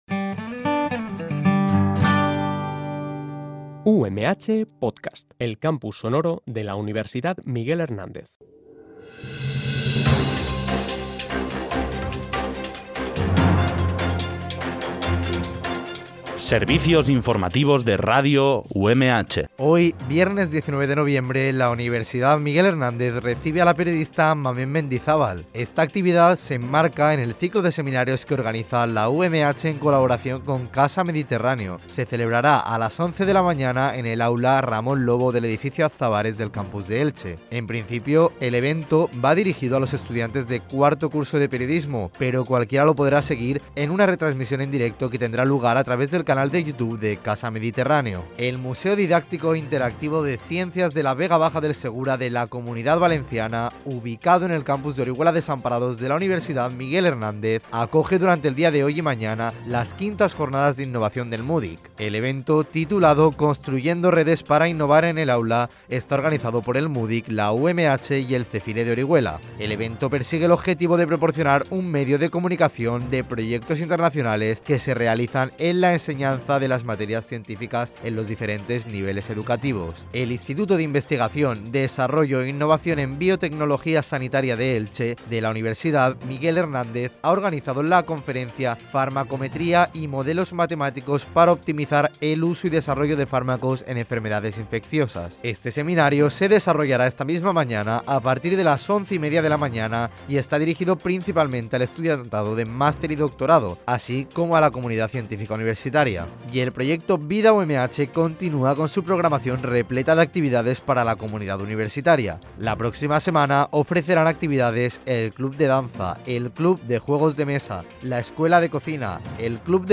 BOLETÍN INFORMATIVO UMH